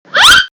Censor - Cartoon slip